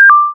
効果音
紙を破る音、ゴクゴク飲む音、トイレの音など・・・、ありそうでなさそな音40点を集めたゲーム用効果音素材集！
正解